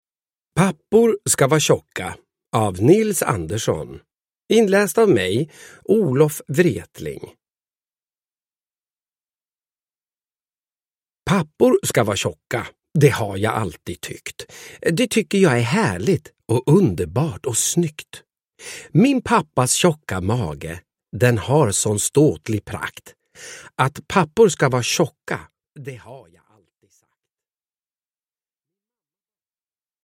Pappor ska va tjocka – Ljudbok – Laddas ner
Uppläsare: Olof Wretling